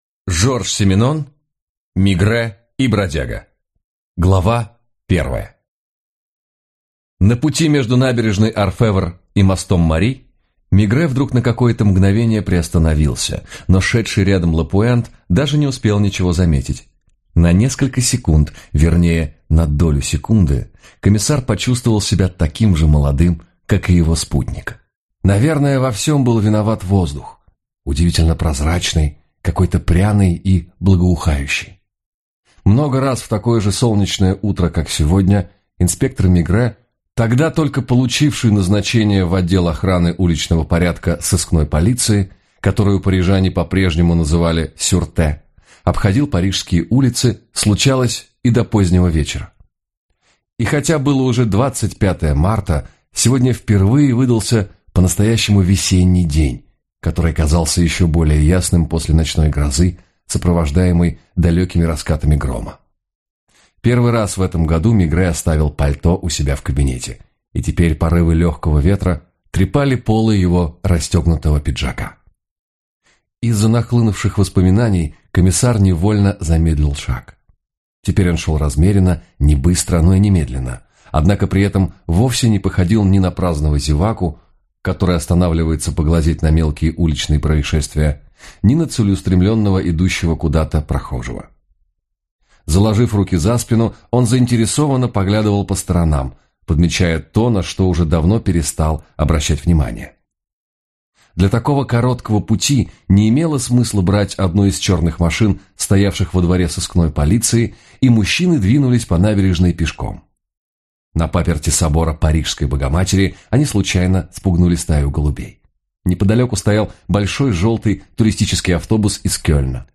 Аудиокнига Мегрэ и бродяга | Библиотека аудиокниг